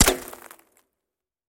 Пуля вонзилась в бетон